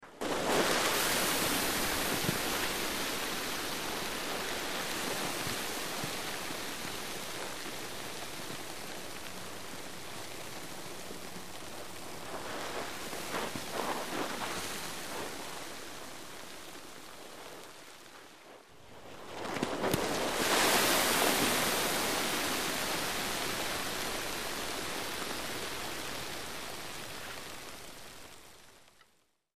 Sand And Pebbles Pouring Movement Could Be A Landslide Or Avalanche Sweetener, X2